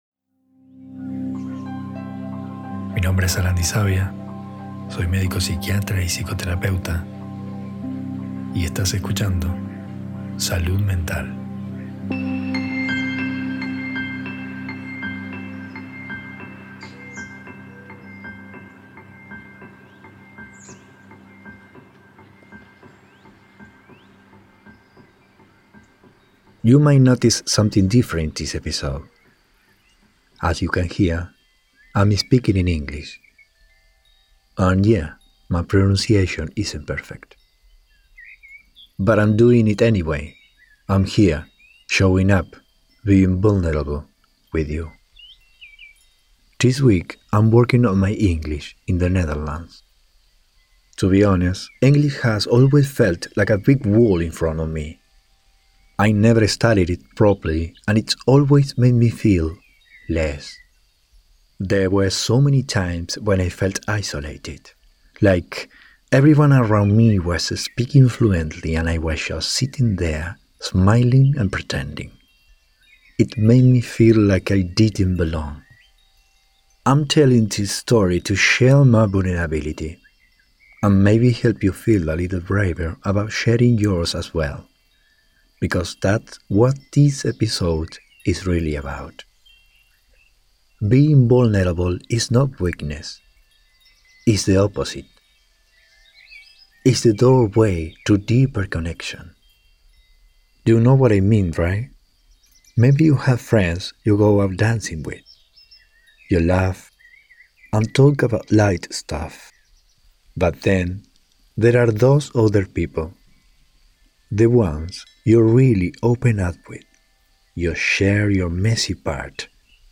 I recorded it while working on my English in the Netherlands — and honestly, it wasn’t easy.